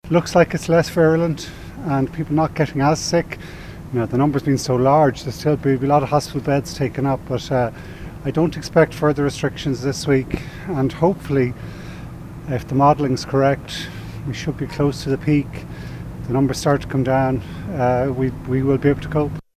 Minister Eamon Ryan says the current measures should be enough to deal with the Omicron strain: